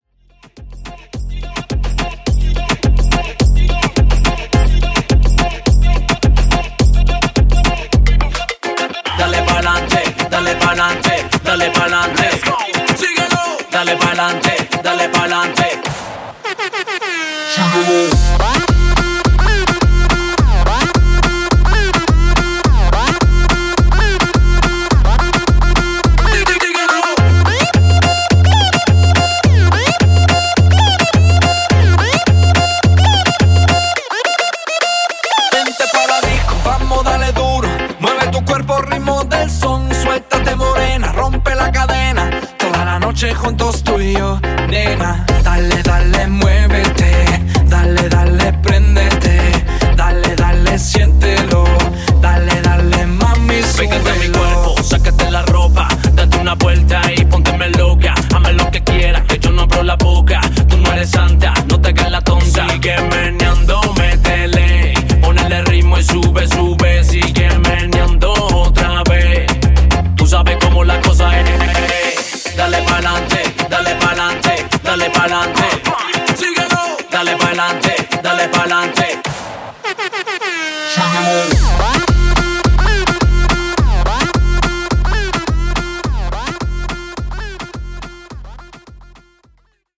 Moombah Rework)Date Added